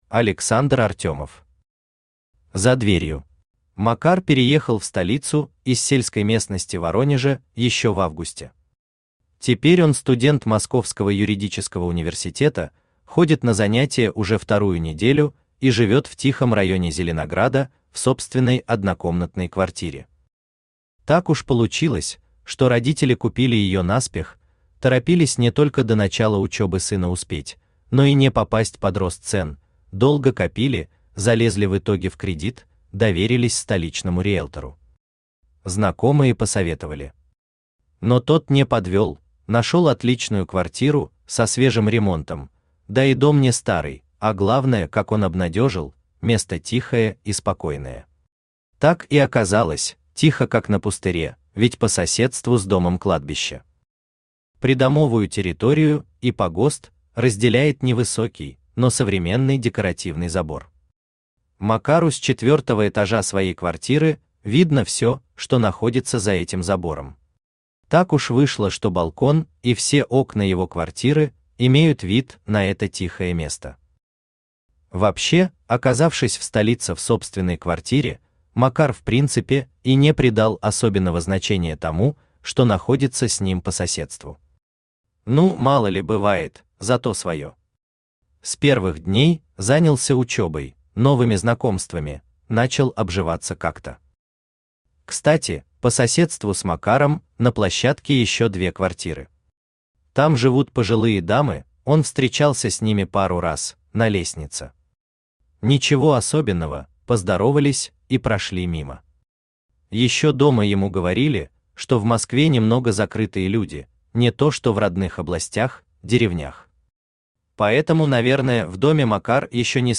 Aудиокнига За Дверью Автор Александр Артемов Читает аудиокнигу Авточтец ЛитРес.